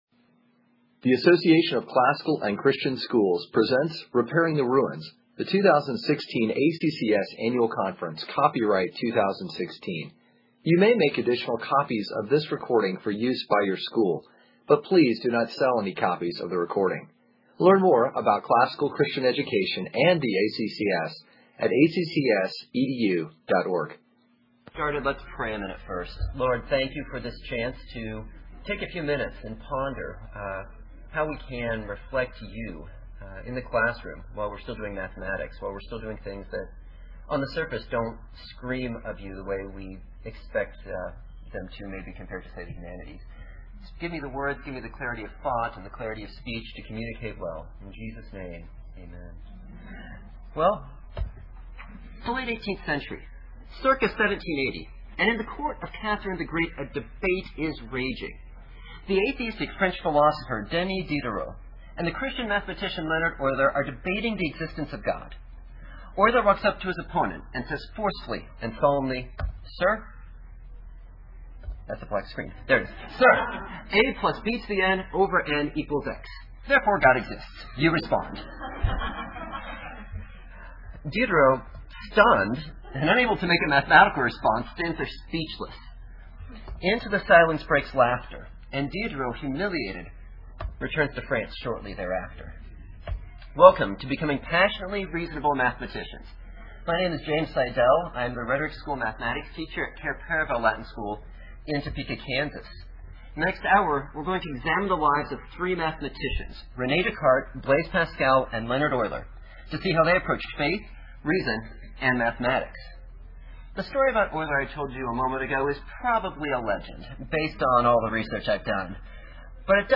2016 Workshop Talk | 1:00:48 | All Grade Levels, Math
Speaker Additional Materials The Association of Classical & Christian Schools presents Repairing the Ruins, the ACCS annual conference, copyright ACCS.